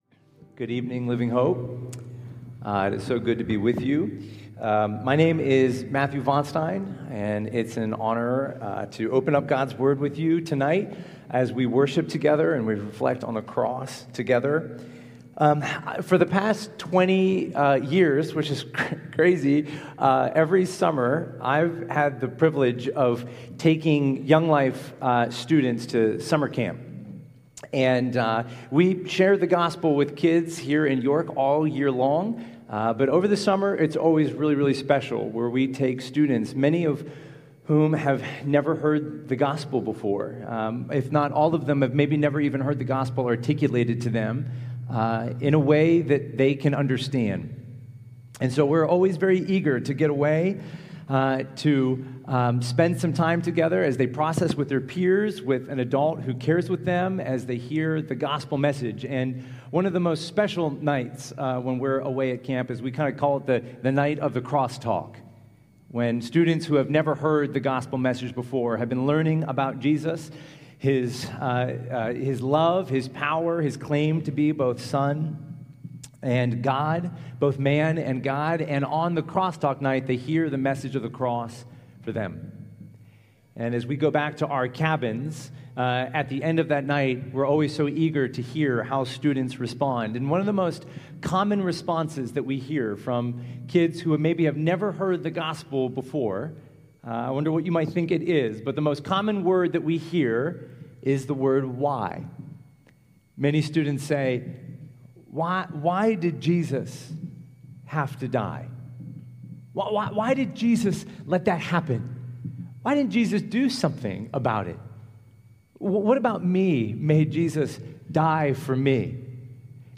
April 3, 2026 – Good Friday Service